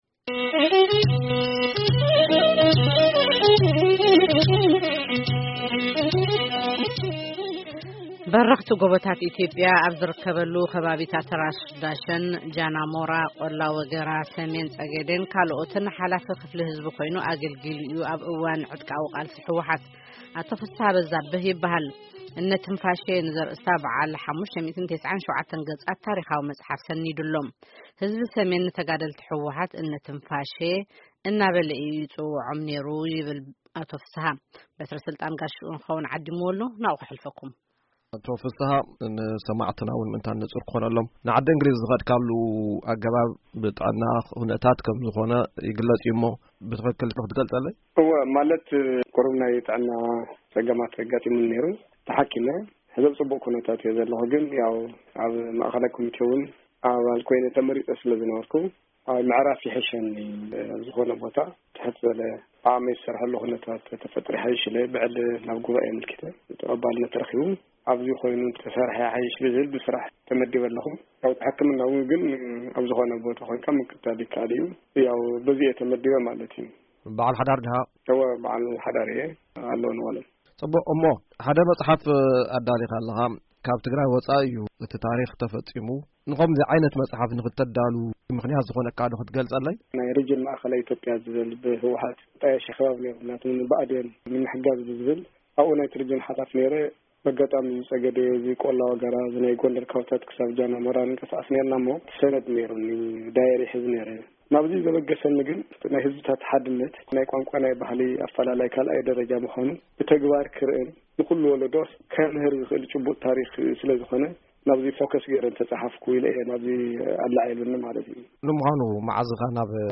ቃለ መጠይቅ